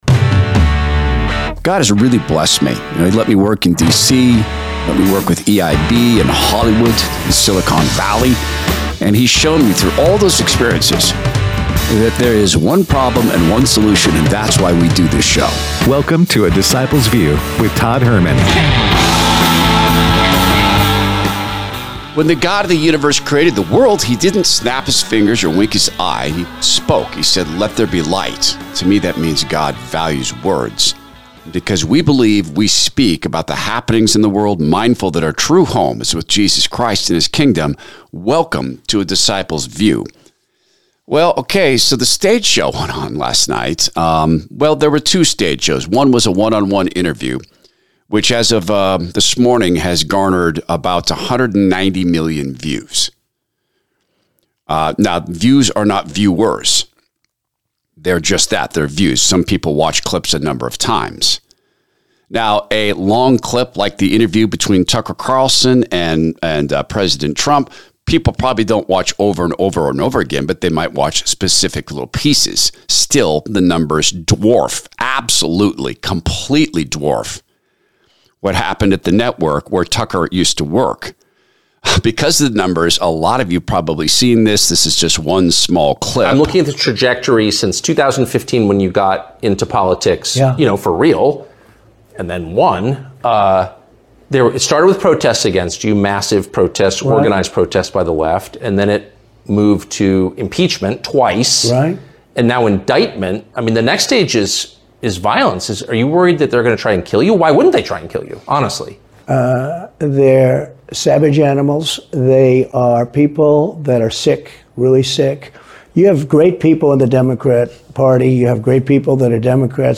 Tucker Carlson's Interview With President Trump